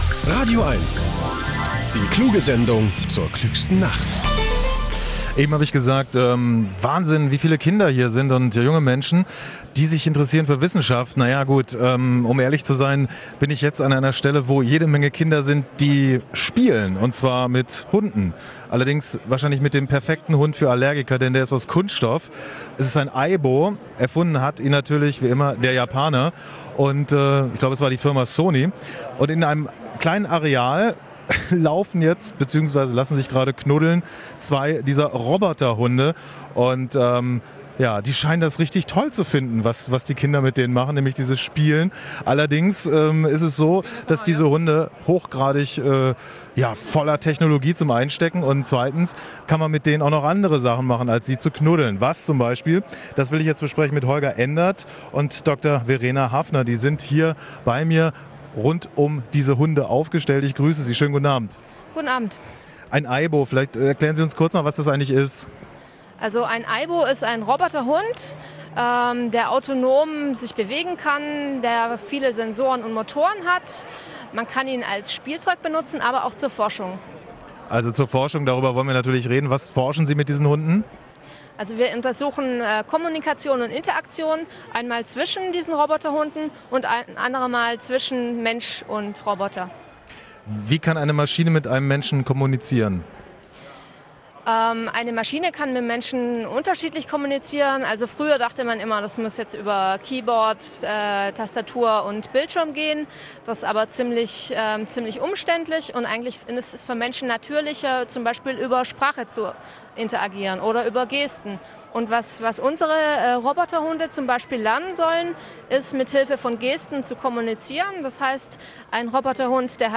Radio1 Interview auf der LNDW zu Aibos, Fussball WM etc. (mp3 /